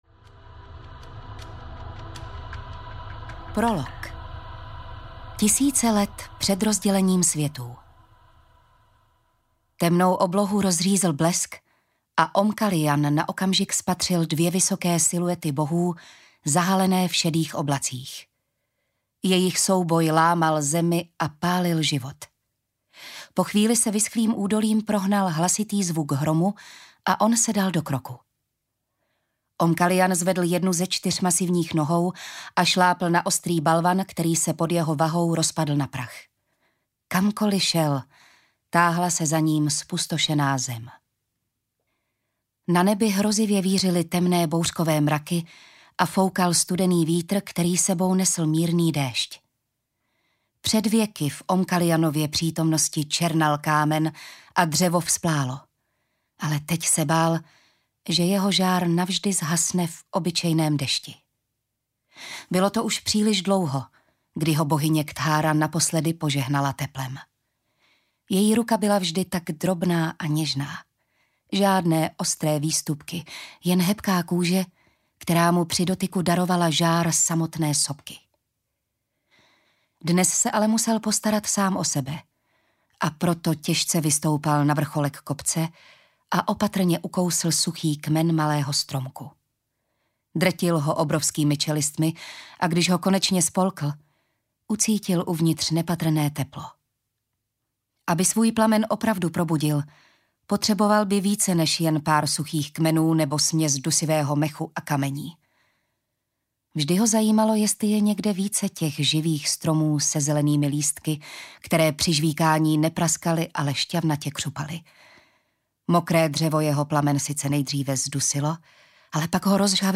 Arila: Poslední hvězda audiokniha
Ukázka z knihy